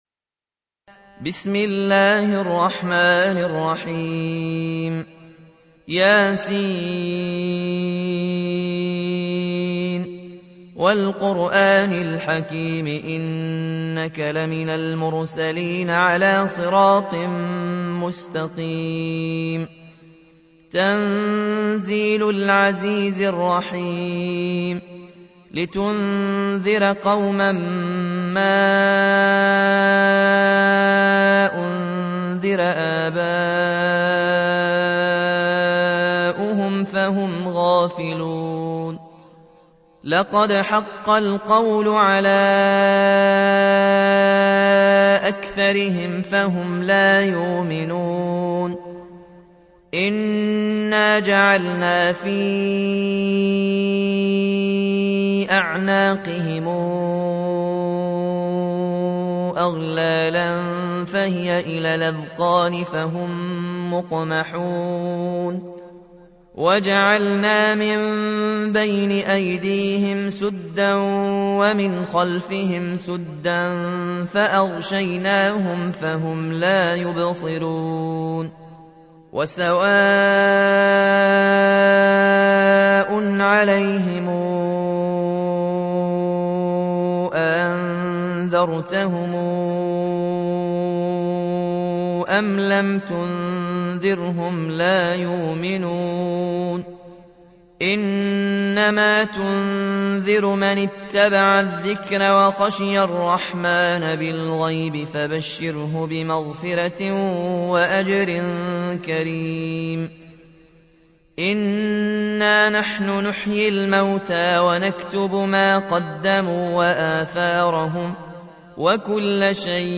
تحميل : 36. سورة يس / القارئ ياسين الجزائري / القرآن الكريم / موقع يا حسين